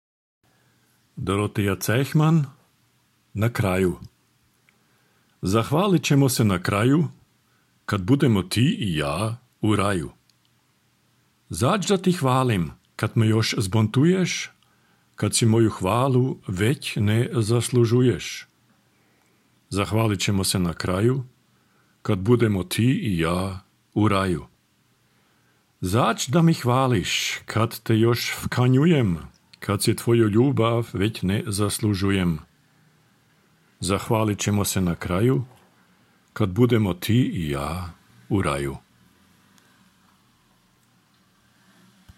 čita